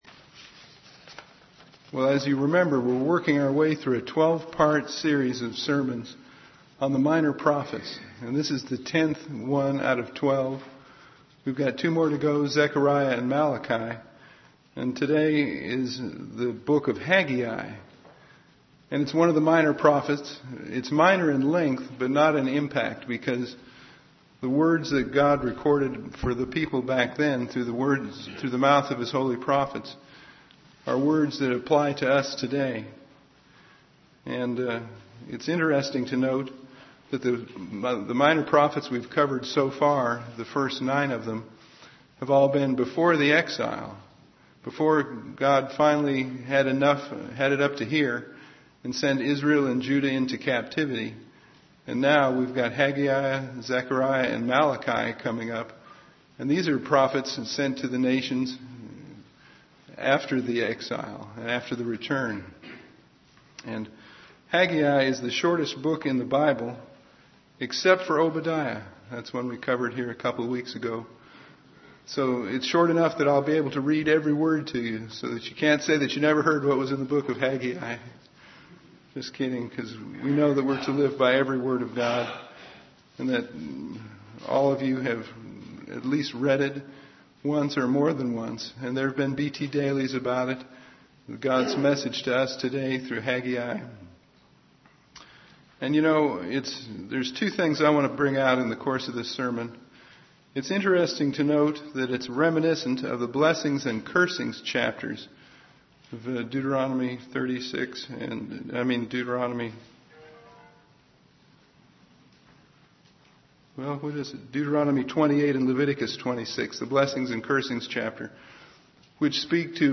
Tenth in a 12-part series of sermons on the Minor Prophets, applying ancient prophecies to today.
Given in Ft. Wayne, IN